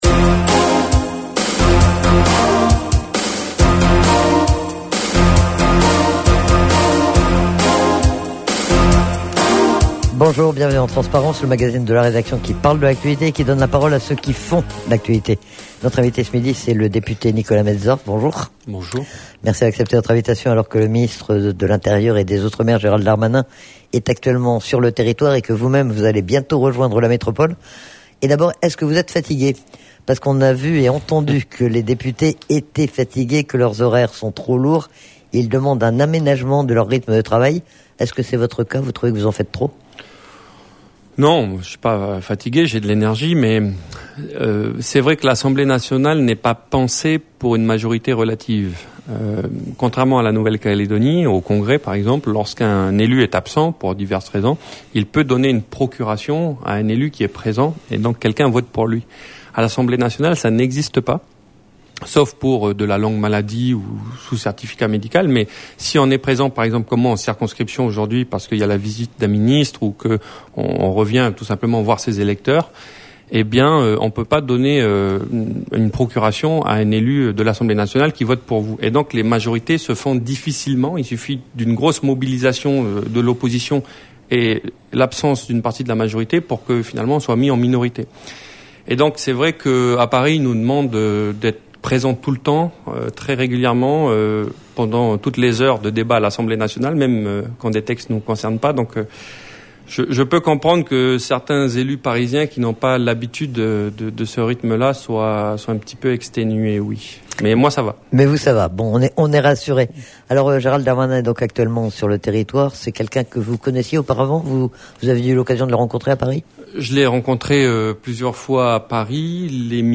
Nicolas Metzdorf était interrogé sur la visite du ministre de l'intérieur et des outremers, Gérald Darmanin mais aussi sur son action de député et, plus largement, sur l'actualité politique calédonienne.